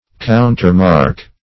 countermark - definition of countermark - synonyms, pronunciation, spelling from Free Dictionary
Countermark \Coun`ter*mark"\ (koun`t[~e]r*m[aum]rk"), v. t.